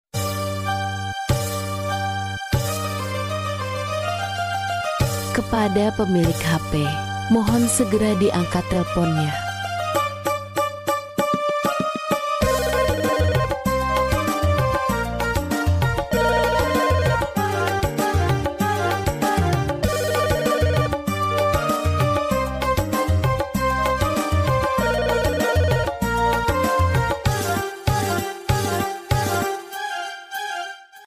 Genre: Nada dering panggilan